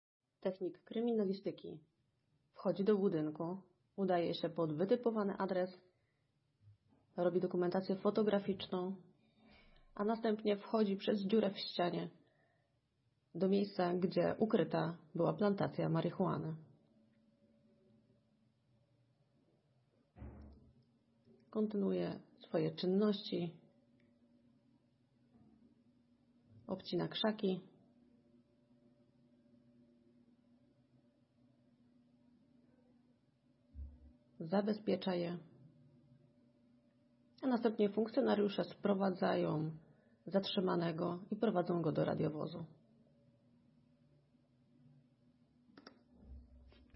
Nagranie audio Audiodeskrypcja filmu